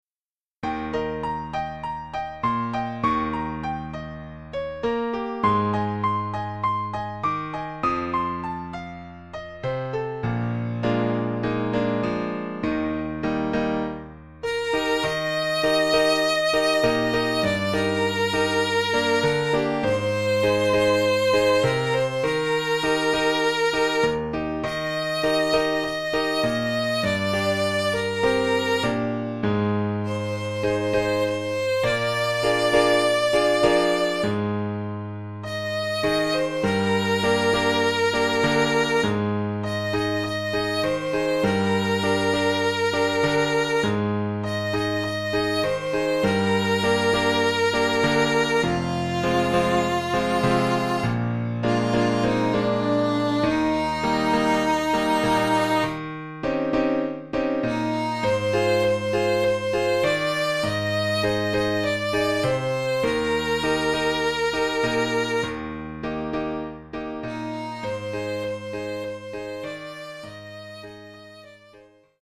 Sample from the Rehearsal CD
Musical comedy play set amongst the staff of a High School.